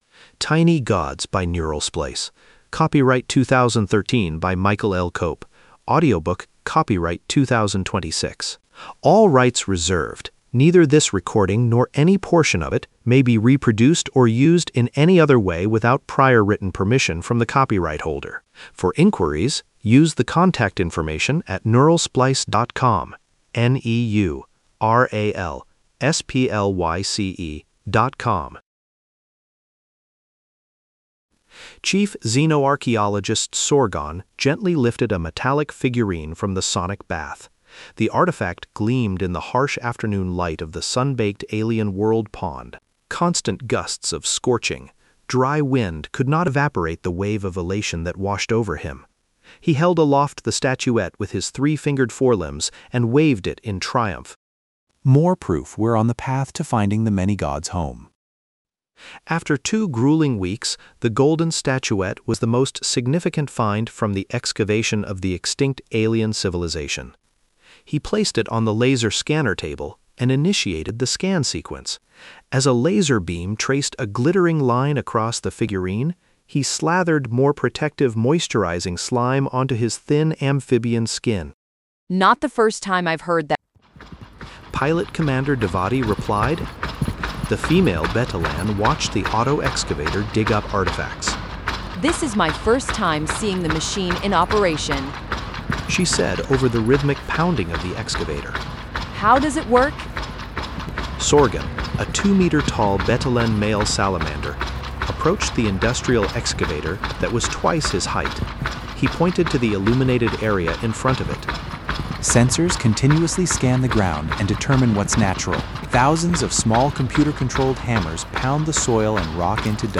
Return to Bookshelf Kneecappers of Rygellia IV Donate up to $3 Download ebook Download audiobook